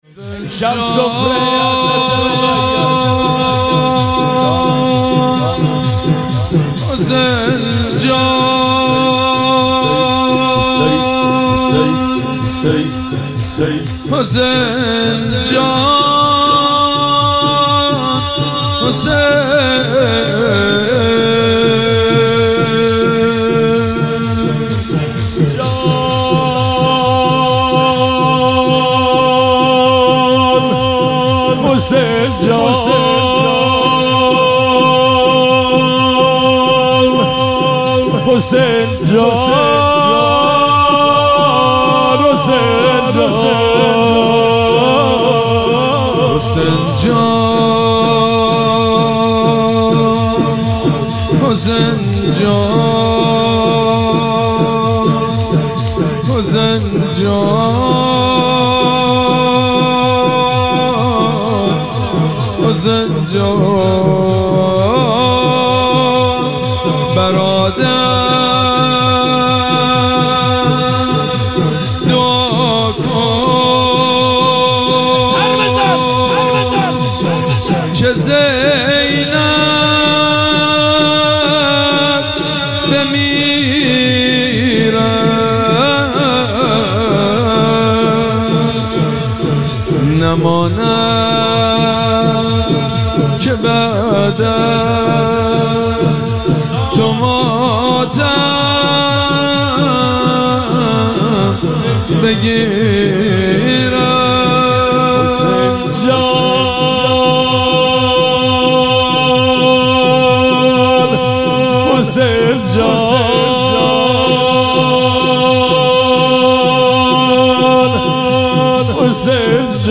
شب سوم مراسم عزاداری اربعین حسینی ۱۴۴۷
نغمه خوانی